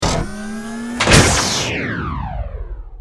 battlesuit_largelaser.wav